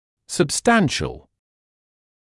[səb’stænʃl][сэб’стэншл]значительный; существенный; крупный
substantial.mp3